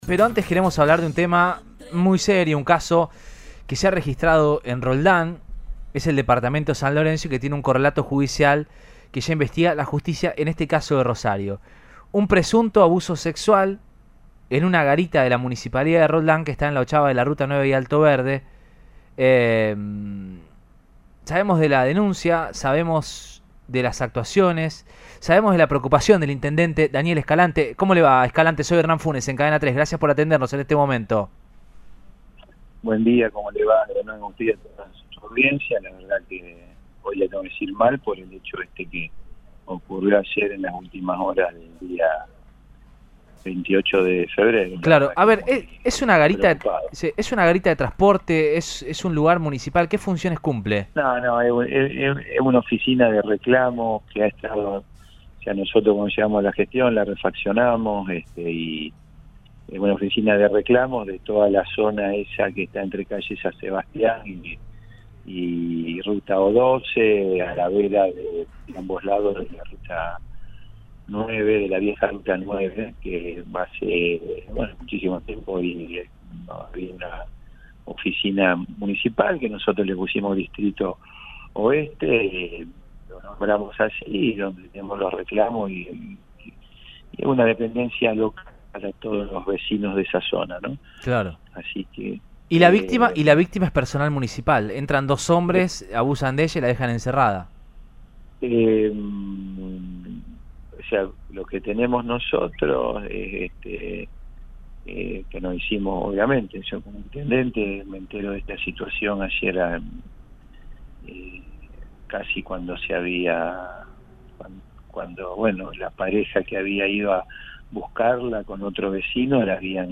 El mandatario municipal Daniel Escalante dialogó con Cadena 3 Rosario y brindó detalles de lo que ocurrió este martes en el Distrito Oeste de esa ciudad.